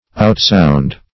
Outsound \Out*sound"\